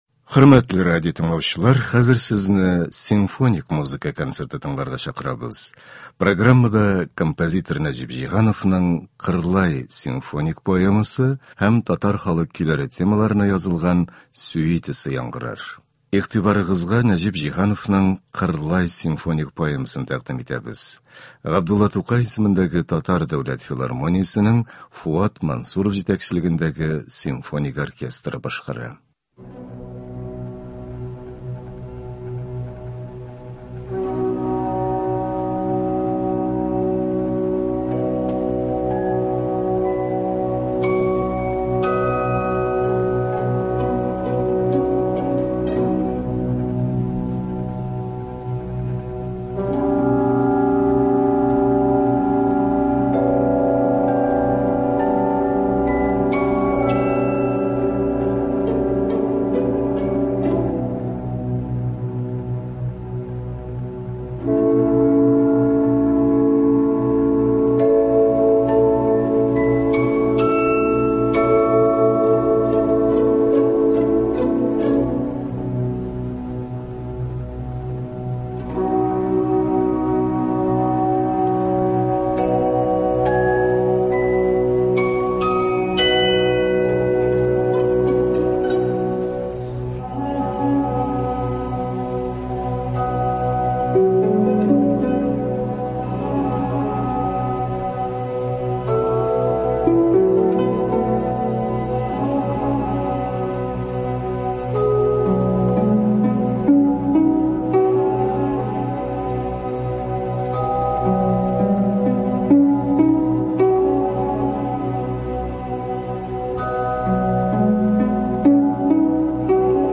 Кичке концерт.